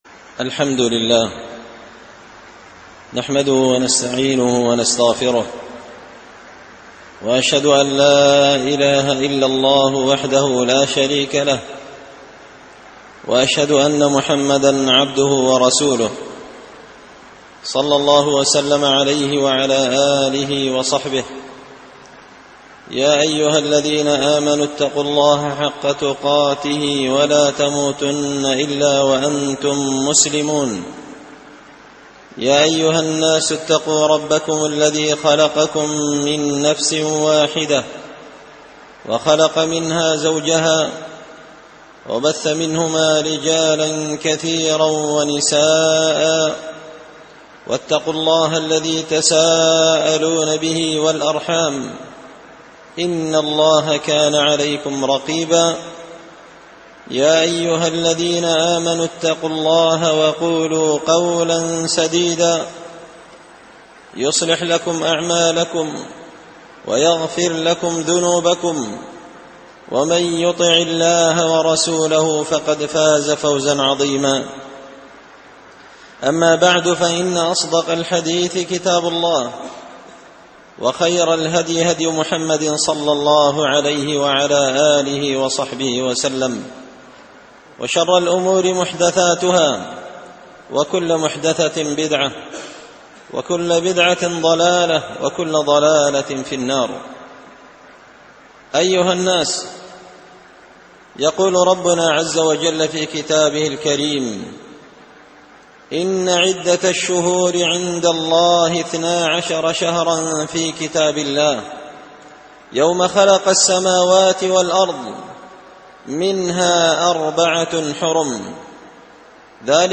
خطبة جمعة بعنوان – آيات وعبر في قصة موسى عليه السلام
دار الحديث بمسجد الفرقان ـ قشن ـ المهرة ـ اليمن